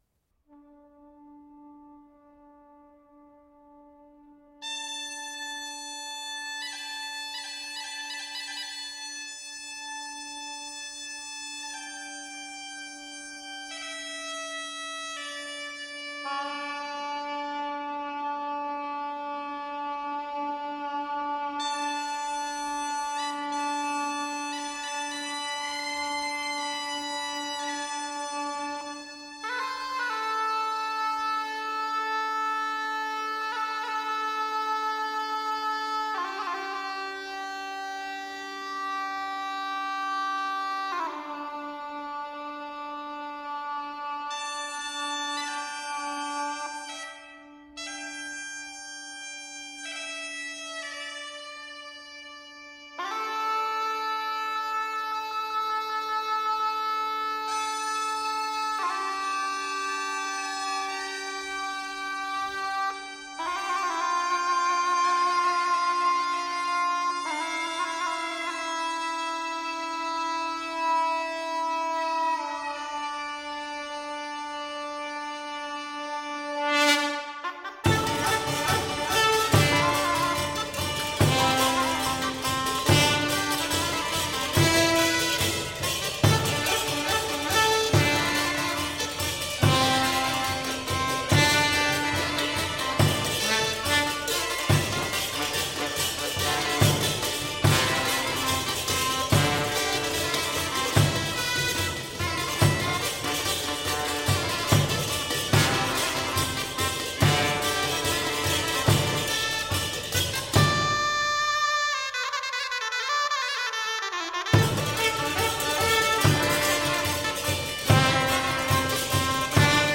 Medieval Dance Music.